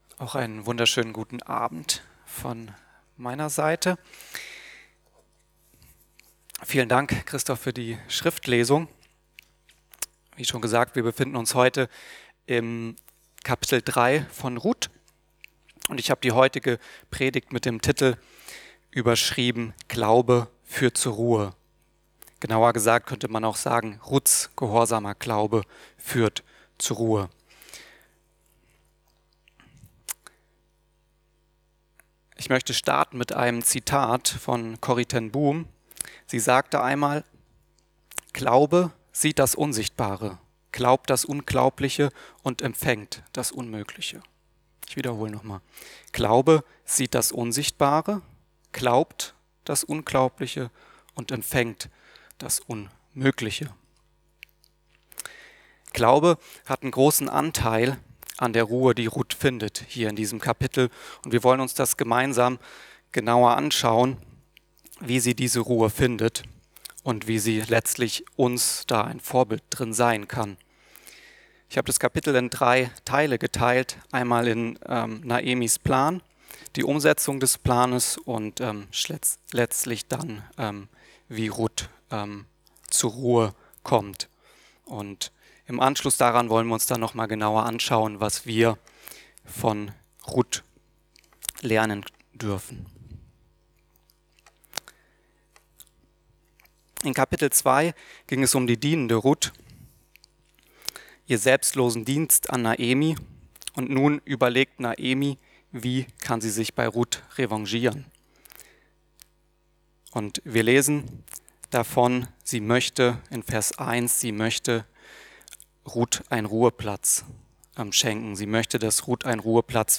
Glaube führt zu Ruhe ~ Mittwochsgottesdienst Podcast